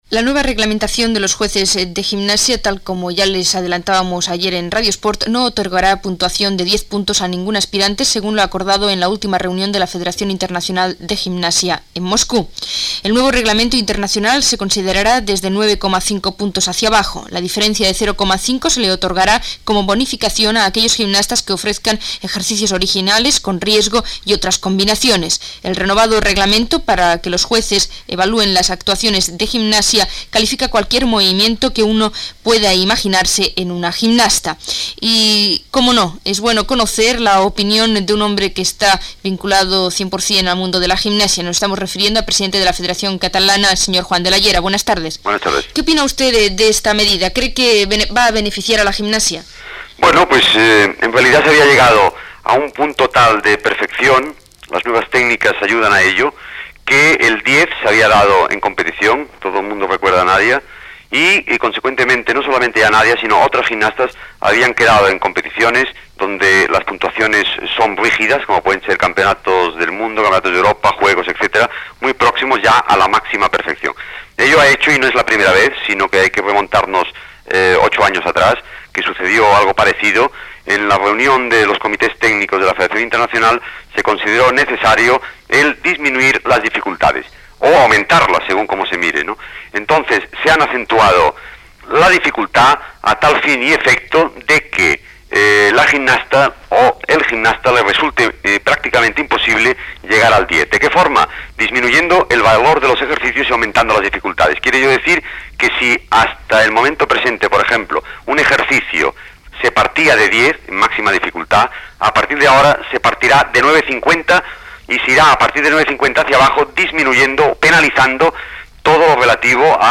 Esportiu